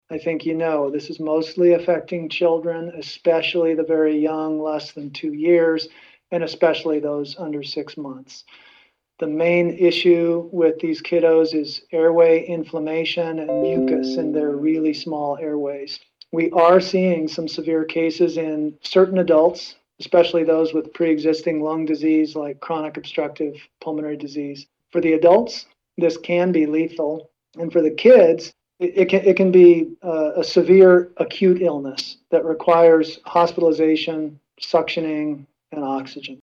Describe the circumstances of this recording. During a press availability, agency officials called the situation a ‘triple-demic’ of flu, respiratory syncytial virus (RSV), and COVID-19.